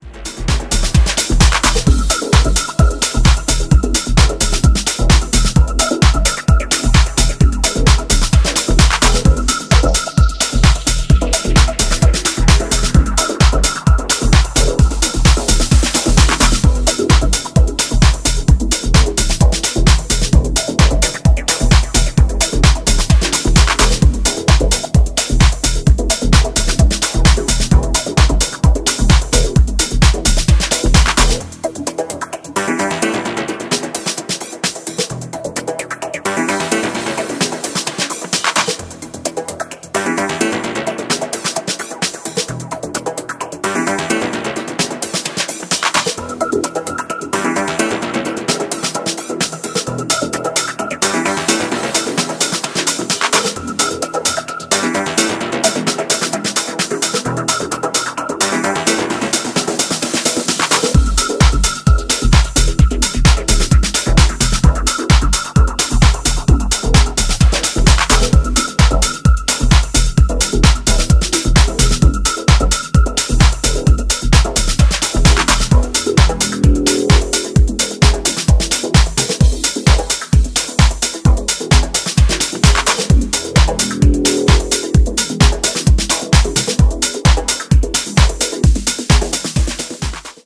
推進力抜群のエネルギーとサイケデリックなテクスチャーが共存した精彩なプロダクションが光ります。